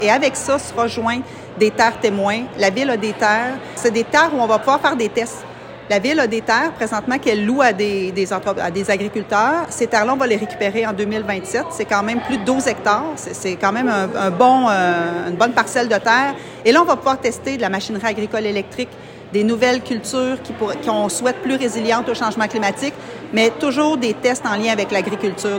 Lors de son discours, la mairesse a également mentionné que la Ville sera en mesure de construire 1 000 nouvelles portes d’ici dix ans.